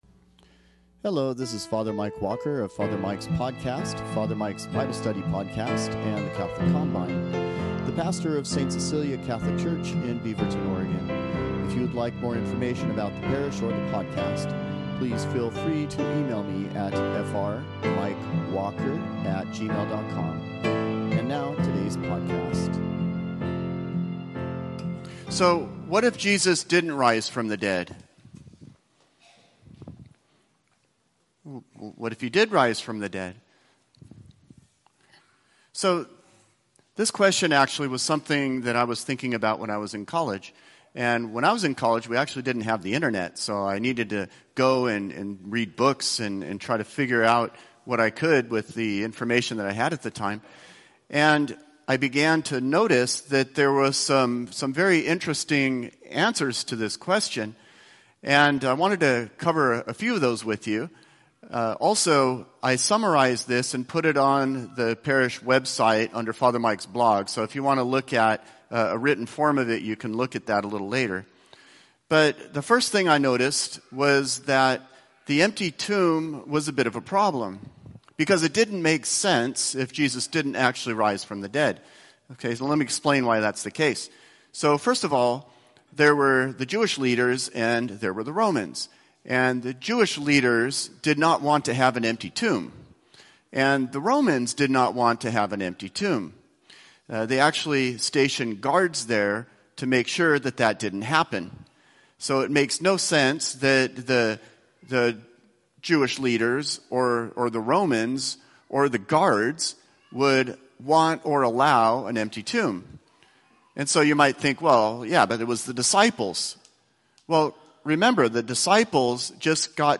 Sunday homilies